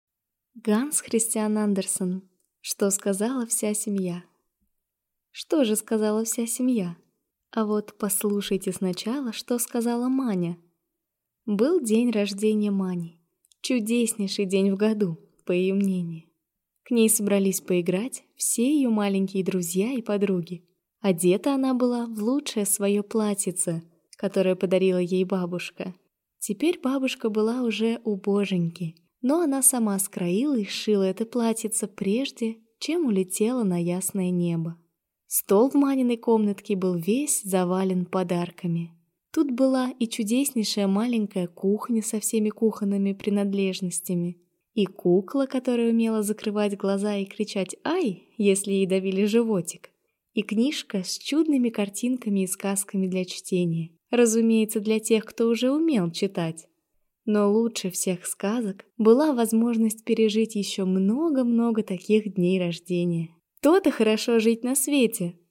Аудиокнига Что сказала вся семья | Библиотека аудиокниг